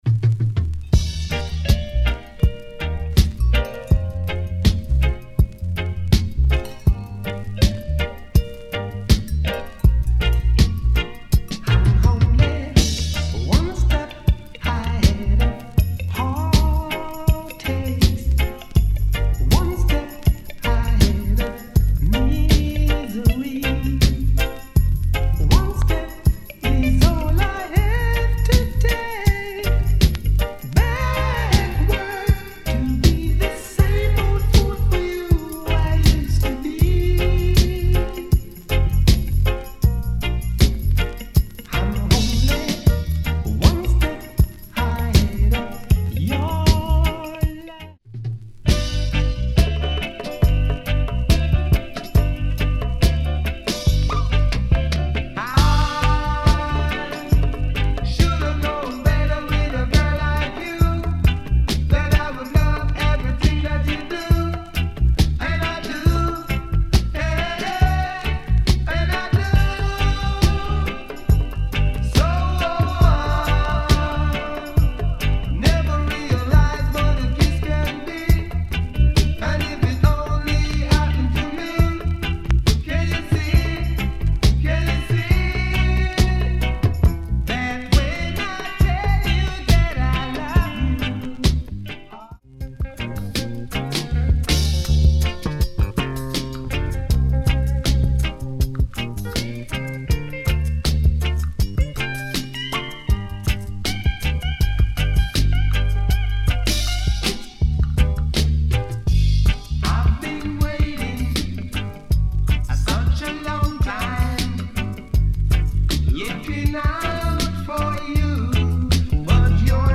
We record our sound files with no EQ is added.